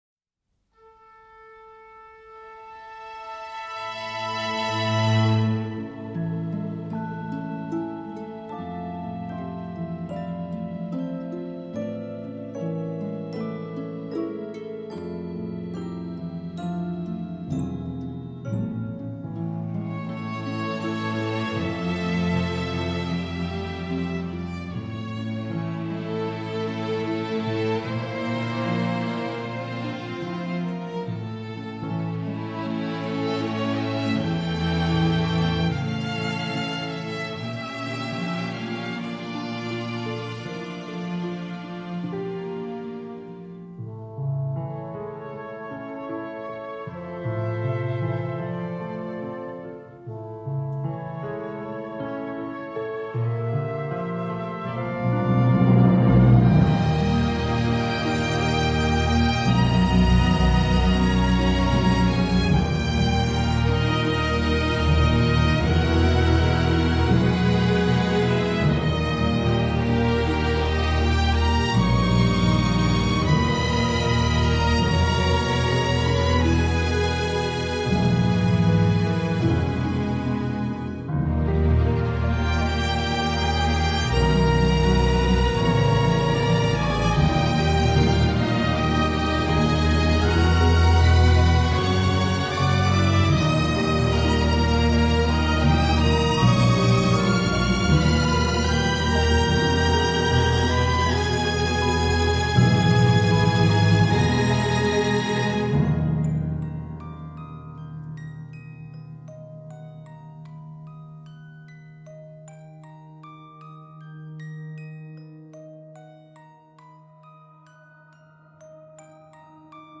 une B.O. romantique en diable